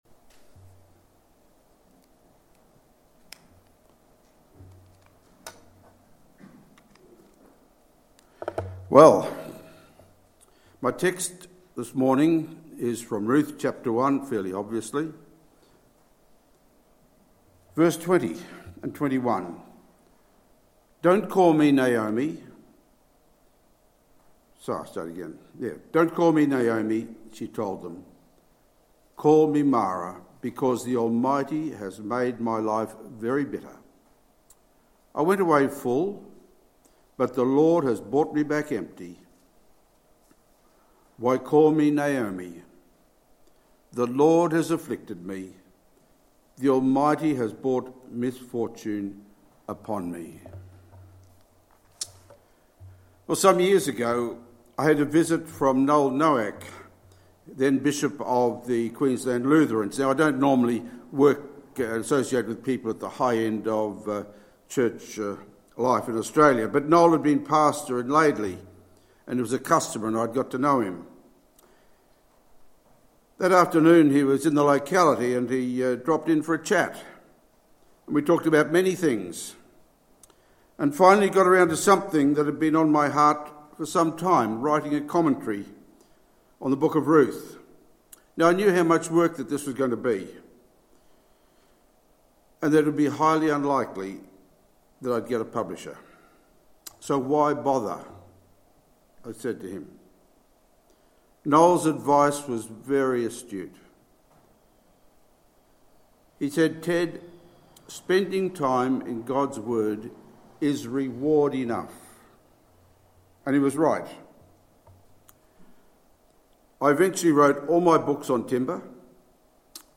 Sermons | Tenthill Baptist Church
06/02/2022 Sunday service